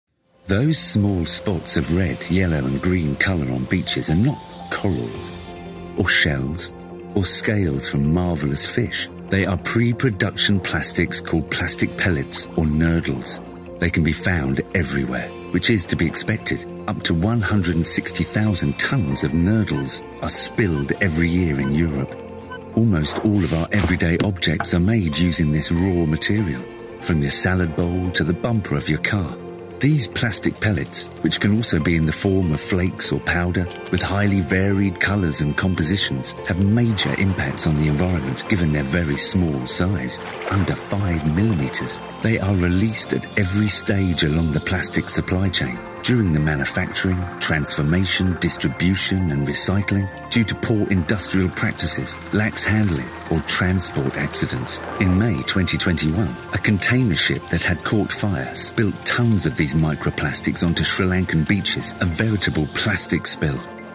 Giọng nam người Anh